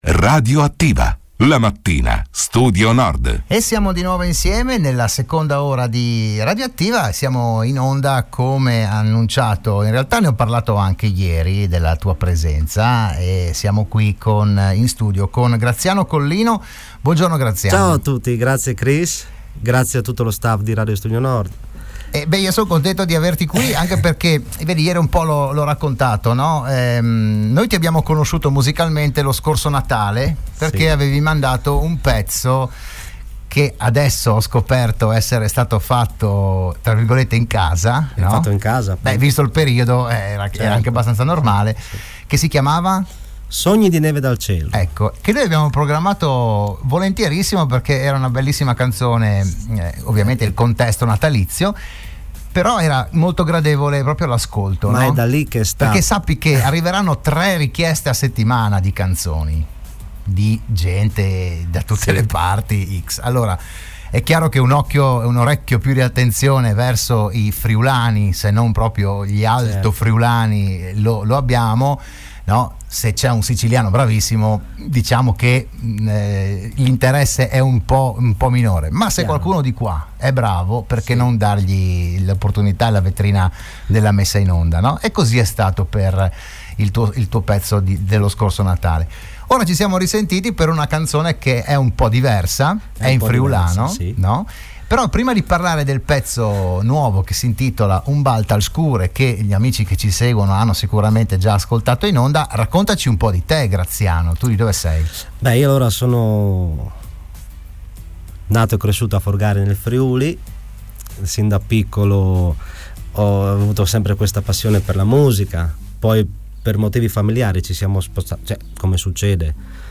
L'AUDIO e il VIDEO dell'intervento a "RadioAttiva" di Radio Studio Nord del cantautore friulano
Proponiamo l’audio e il video del suo intervento odierno a Radio Studio Nord.